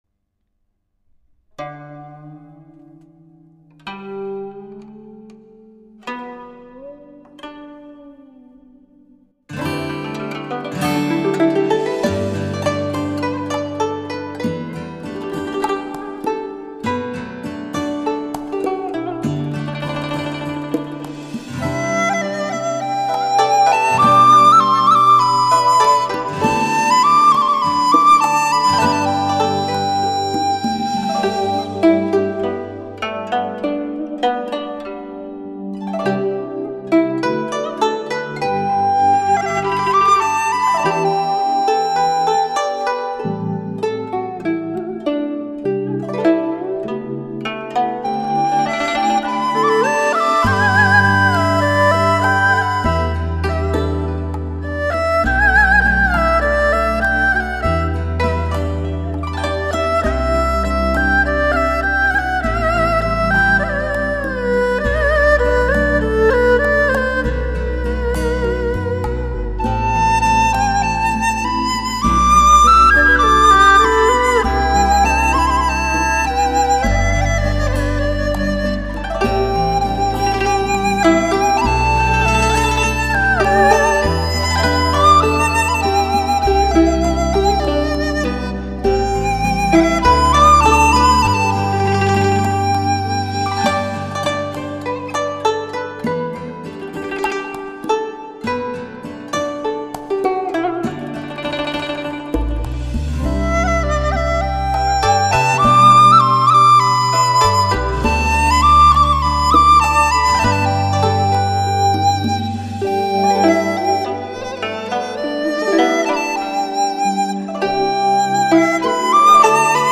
HI-FI典范，如歌旋律 完美演奏，完美的录音浑然天成。
音域宽广，却又细腻绵绵，音质清纯之美令人陶醉，营造出一个充满活力的HIFI场面，